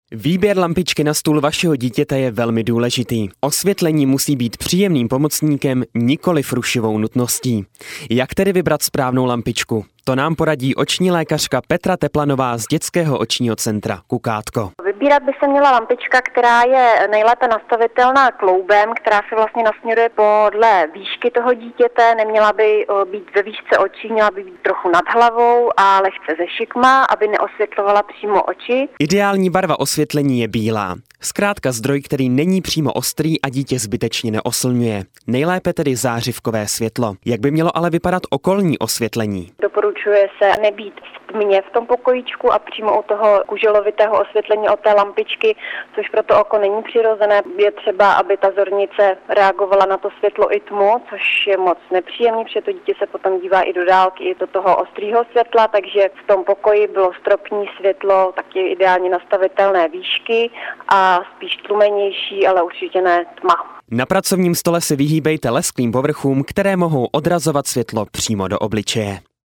záznam rozhovoru, kde paní doktorka radí, jak  vybrat vhodnou lampičku i jak následně dětský stůl dobře osvětlit.